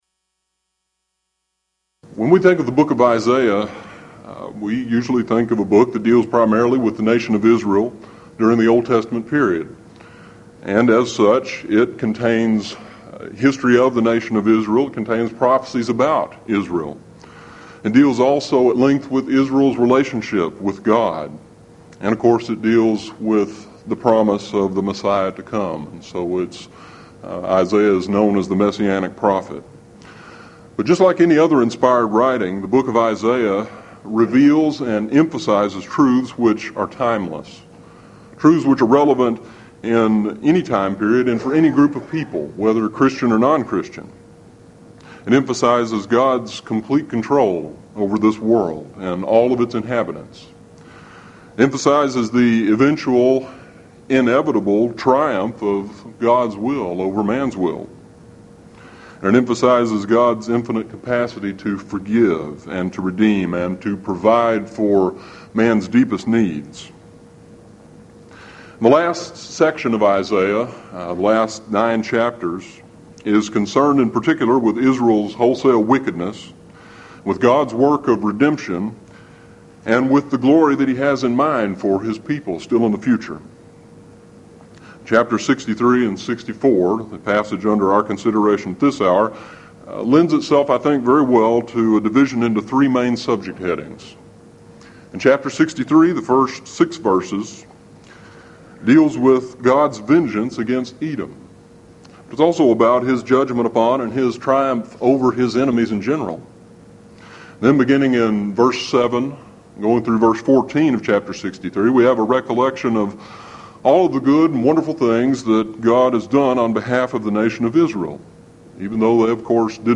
Event: 1996 HCB Lectures